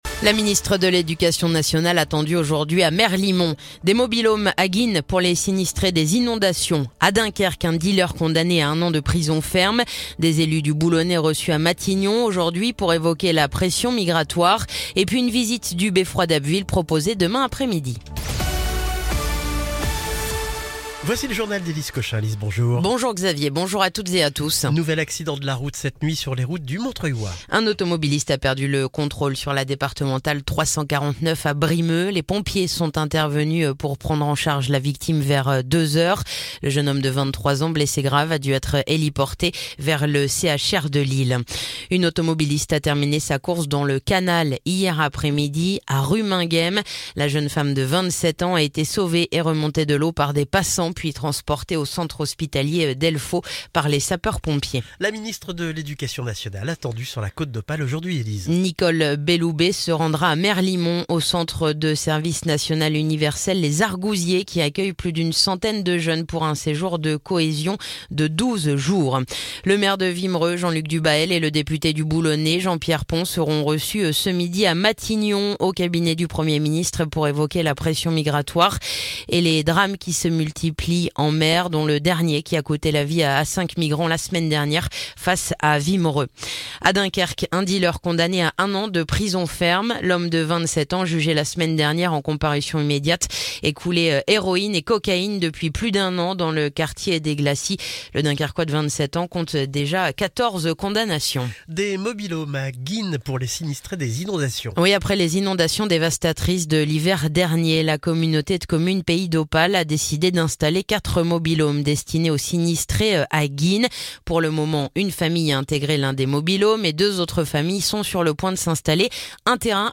Le journal du jeudi 2 mai